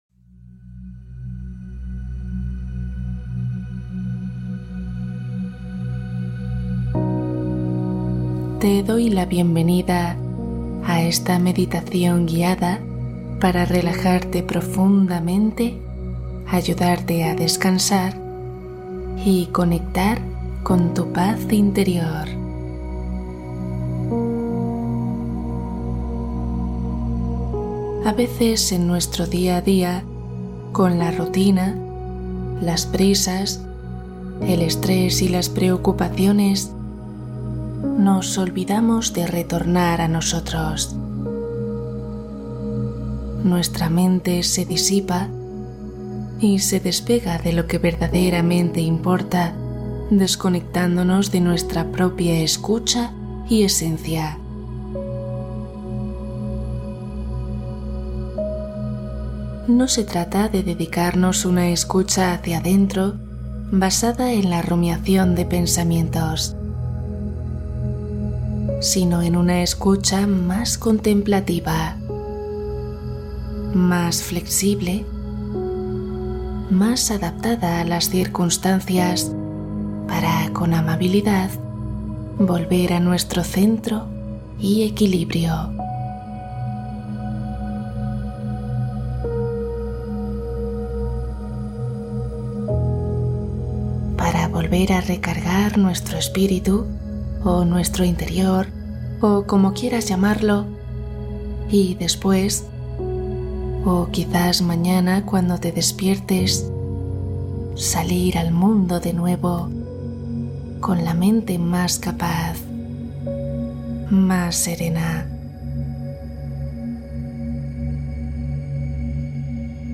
Nuevos inicios ✨ Meditación guiada para sueño profundo